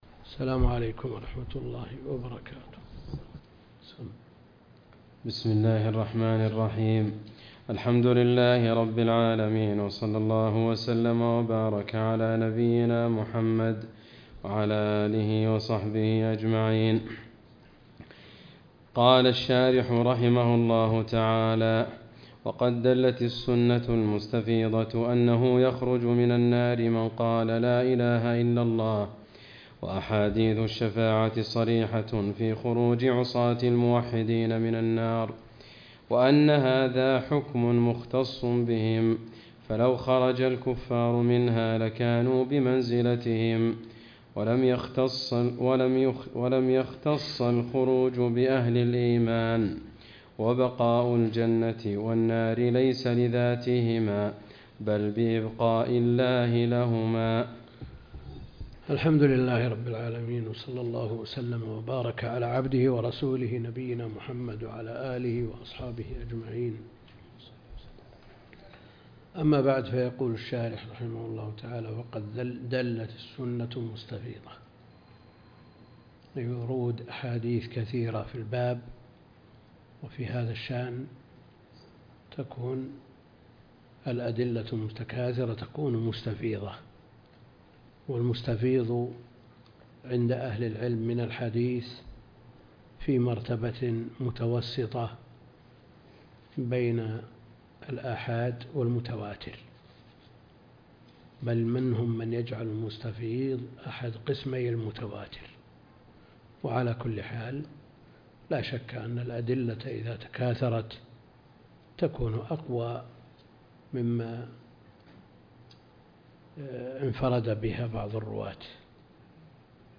الدرس (64) شرح العقيدة الطحاوية